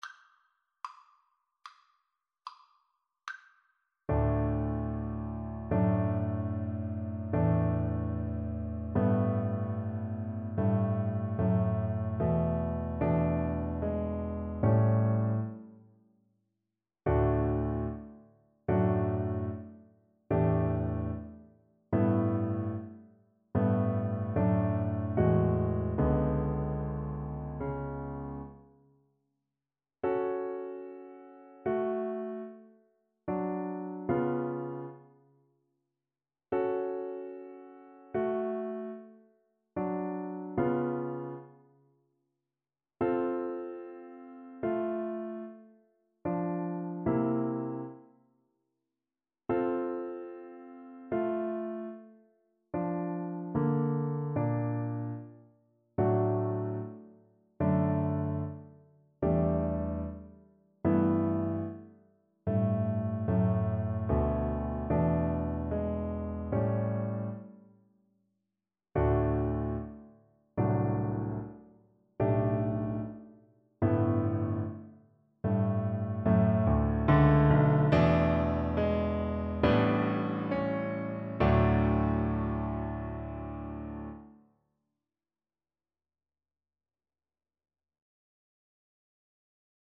• Unlimited playalong tracks
Classical (View more Classical Trombone Music)